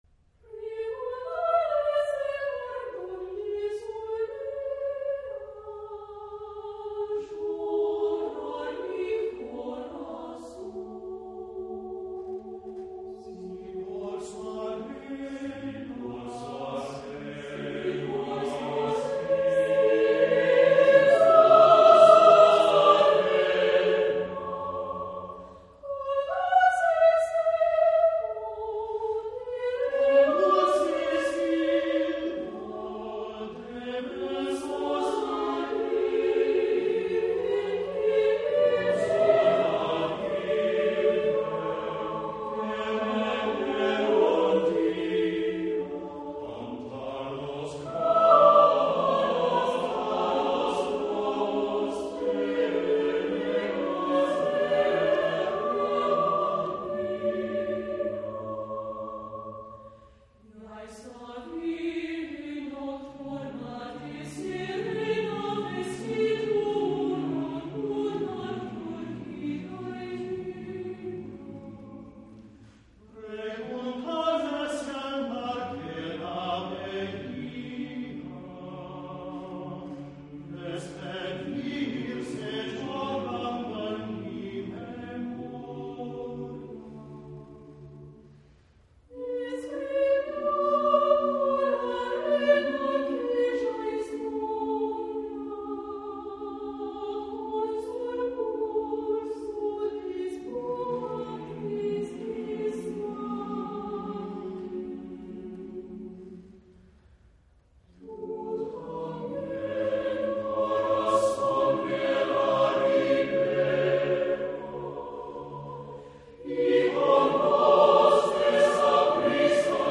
SATB (4 voices mixed).
Partsong.